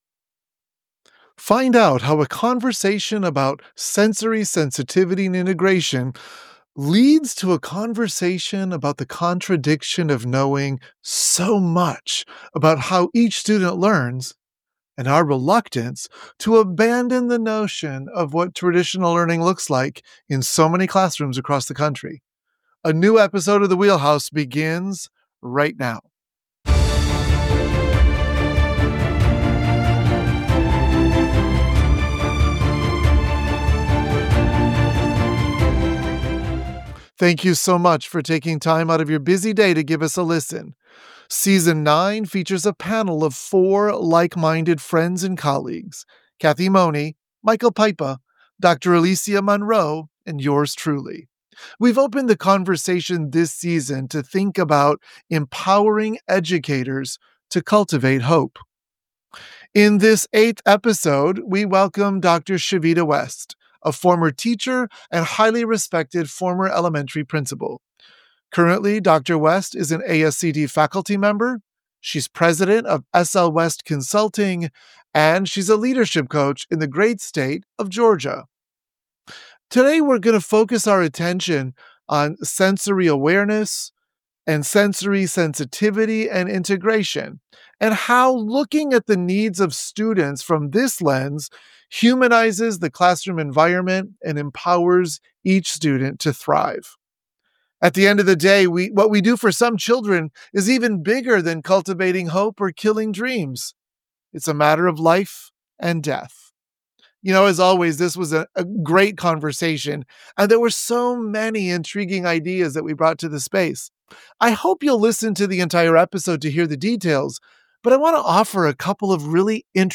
This conversation elucidates the necessity of reimagining educational spaces to accommodate individual learning preferences, thereby cultivating hope and resilience among all students.